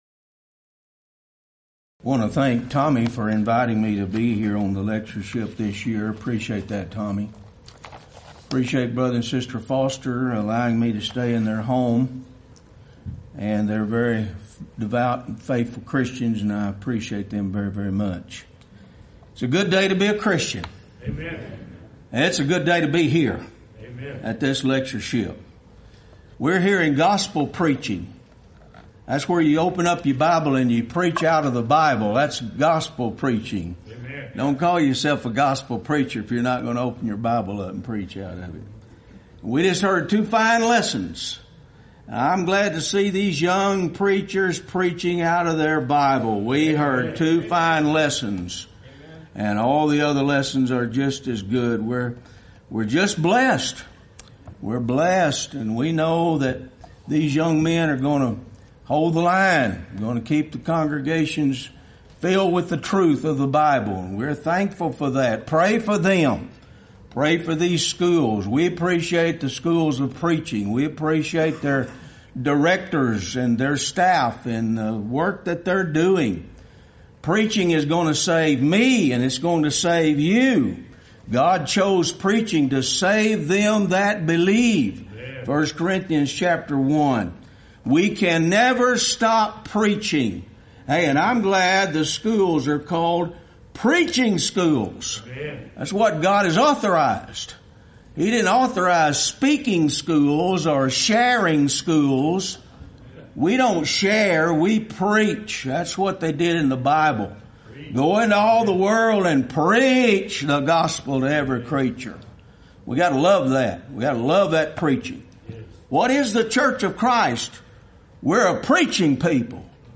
Event: 26th Annual Lubbock Lectures
lecture